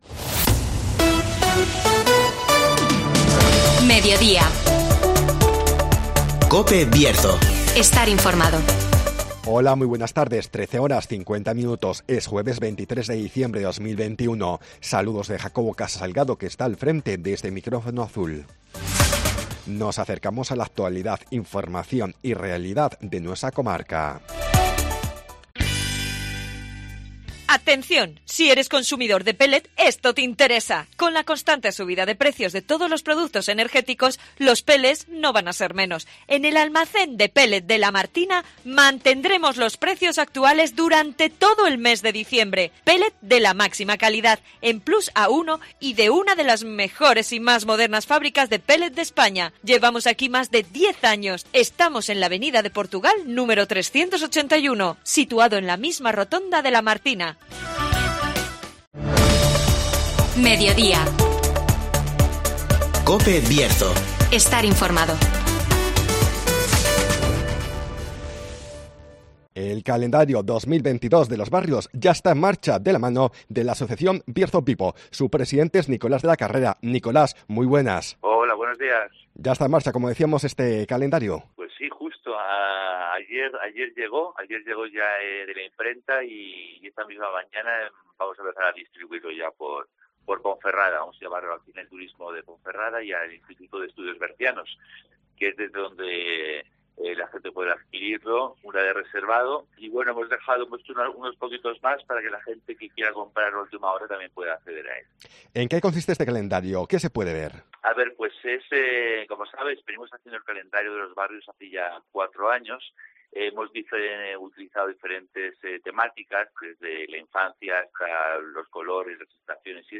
El calendario 2022 de Los Barrios ya está en marcha (Entrevista